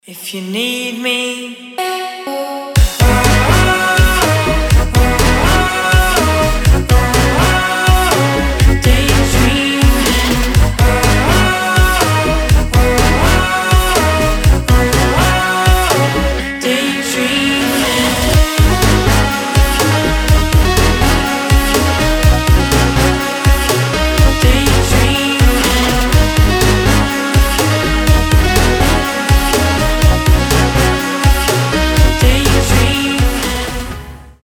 • Качество: 320, Stereo
мужской вокал
мелодичные
dance
EDM
house
Красивая музыка с приятным мужским голосом